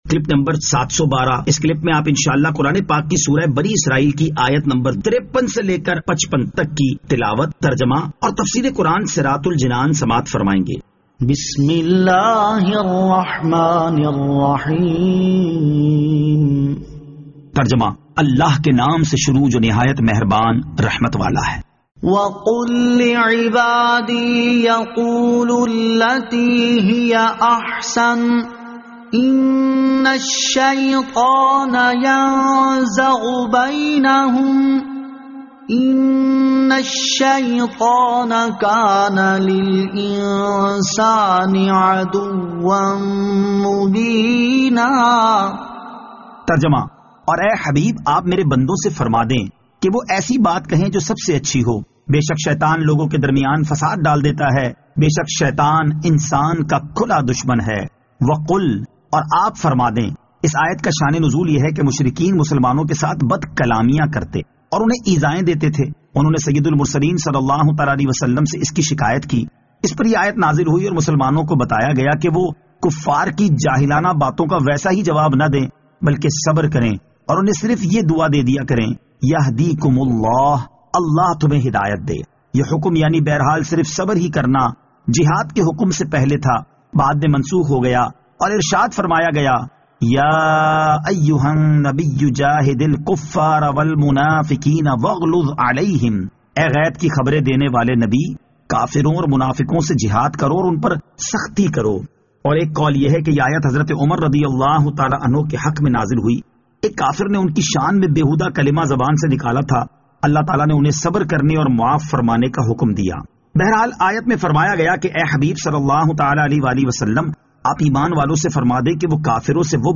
Surah Al-Isra Ayat 53 To 55 Tilawat , Tarjama , Tafseer